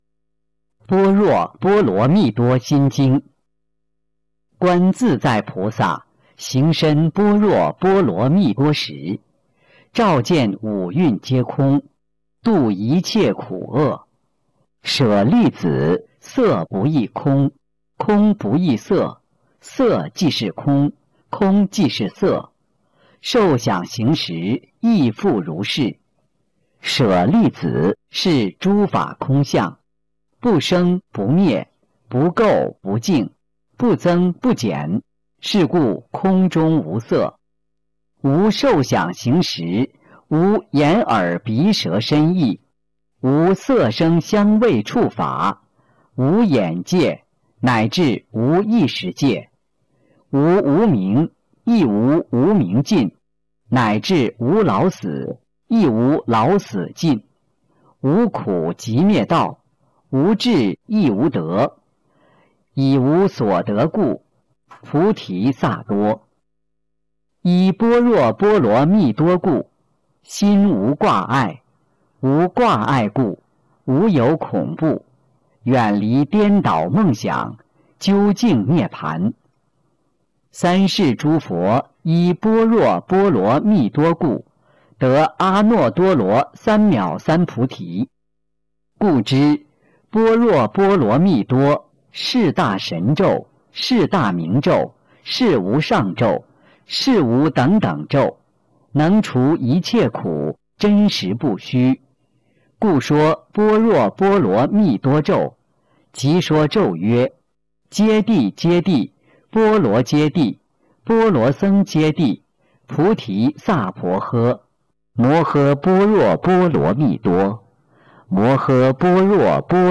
003《心经》教念男声